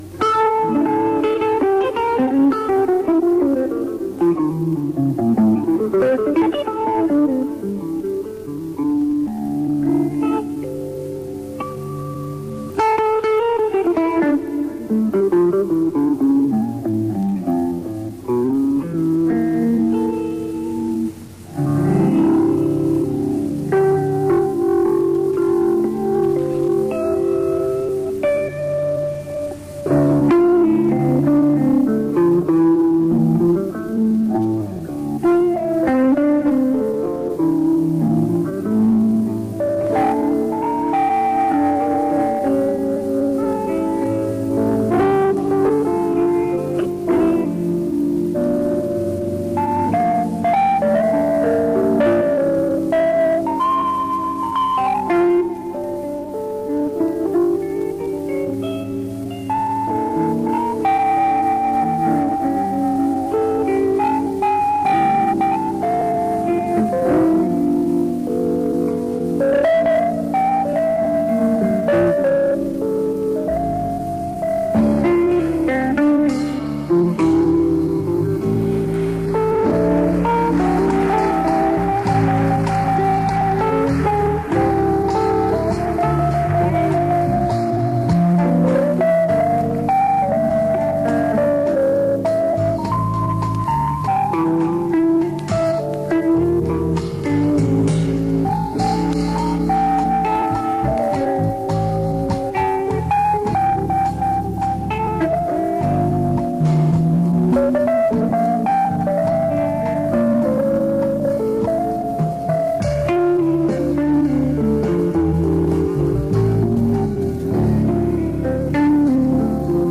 Джаз, любимый.
Писано с эфира в 1993-м на магнитоле, качество не лучшее.